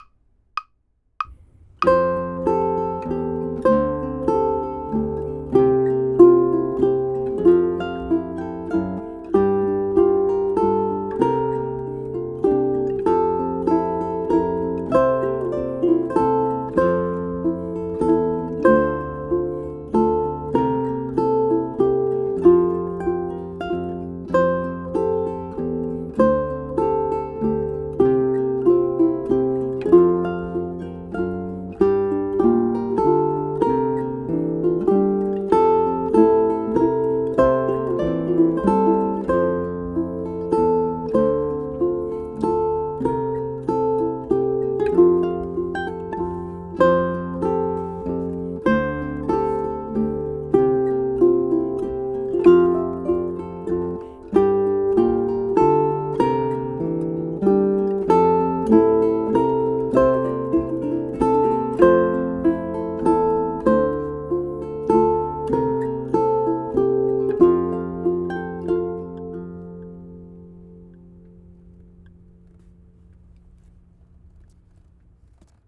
The key signature with a single B-flat—the key of F major—is extremely common in 'ukulele music.
ʻukulele